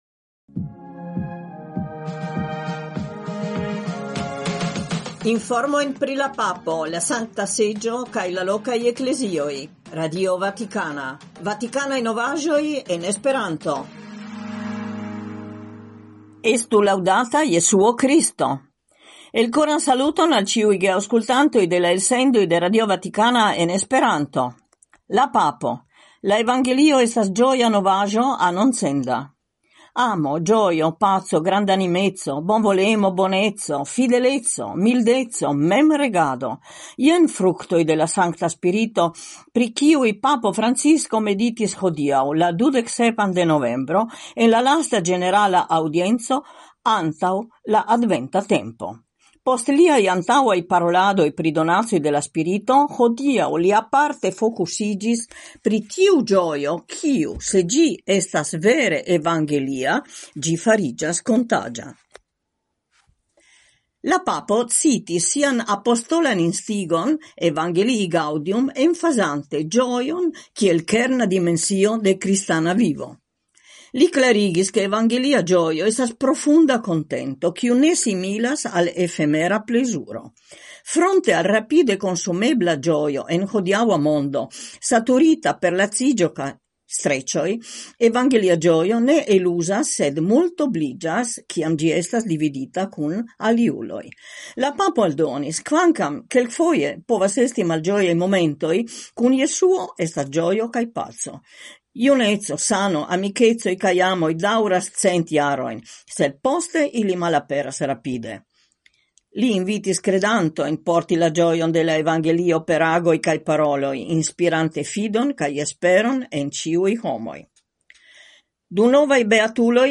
Elsendoj kun informoj el Vatikano en esperanto (trifoje semajne, merkrede, ĵaŭde kaj dimanĉe, horo 20.20 UTC). Ekde 1977 RV gastigas elsendojn en esperanto, kiuj informas pri la agado de la Papo, de la Sankta Seĝo, de la lokaj Eklezioj, donante spacon ankaŭ al internaciaj informoj, por alporti la esperon de la kredo kaj proponi interpreton de la faktoj sub la lumo de la Evangelio.